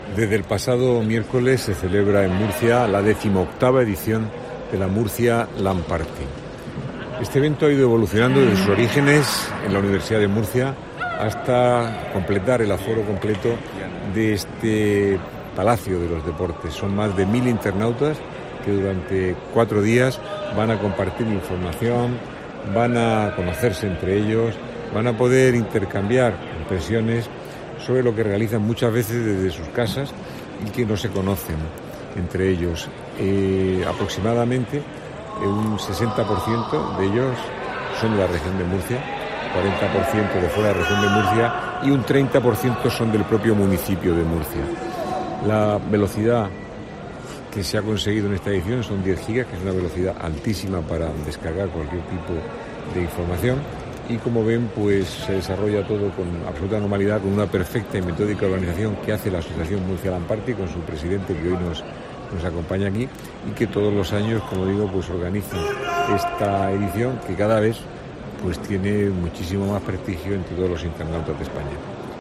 José Ballesta, alcalde de Murcia, visita a la Lan Party